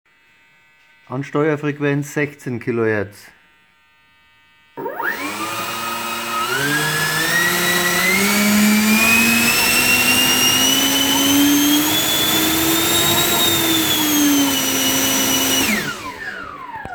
Kurz vor Halbgasdrehzahl kommt ein "leicht pfeifender Ton" zu dem ansonsten normalen Laufgeräusch hinzu.
Bei 16KHz ist das Geräusch am angenehmsten, aber nicht ganz weg.
Die MP3 Files sind von einem Impeller.
16KHZAnsteuerungTiming5.wav